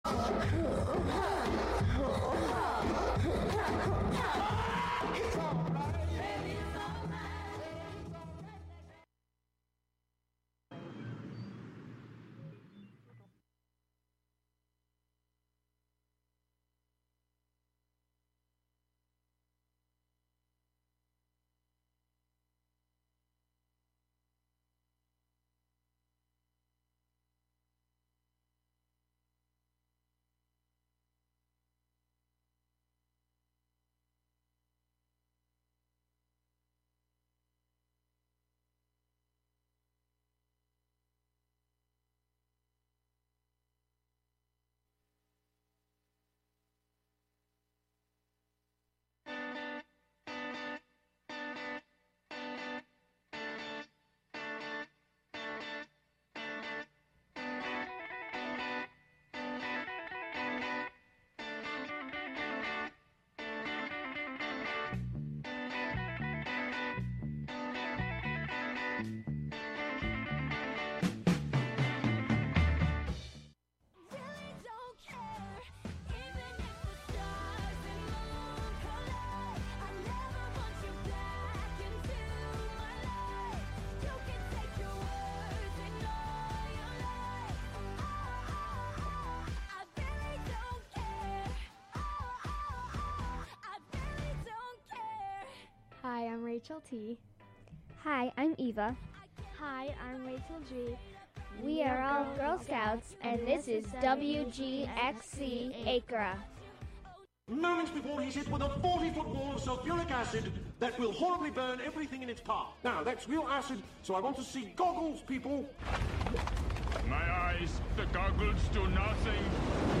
short stories and fables from around the world that are available in print will be the only topic here. i won’t offer commentary or biographies on-air. just the fictions read for the listener as best as i can read them.